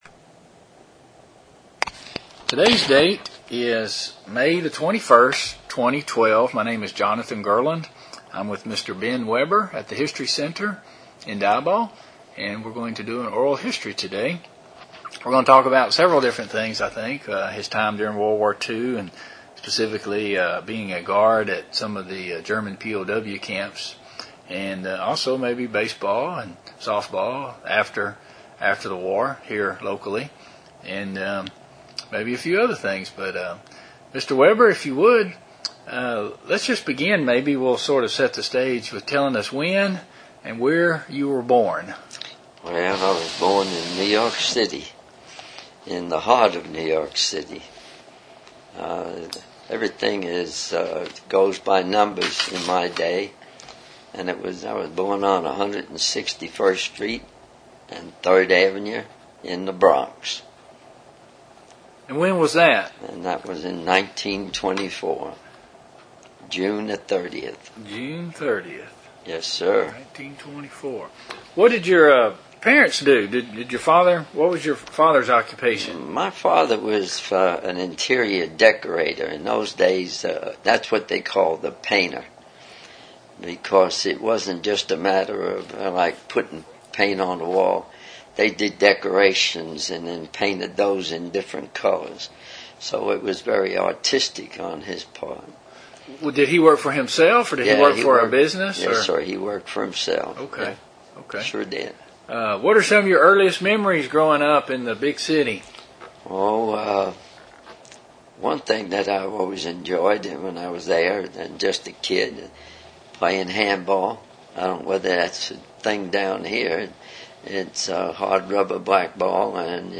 Interview 251a